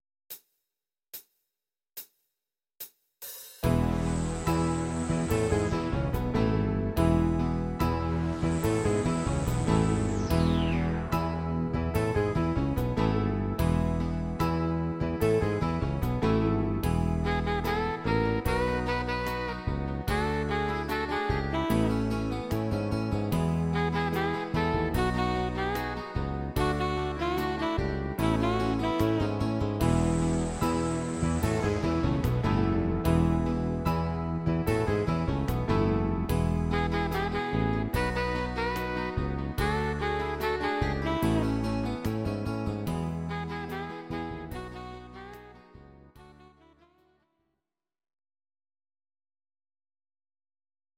Audio Recordings based on Midi-files
Our Suggestions, Pop, German, 1970s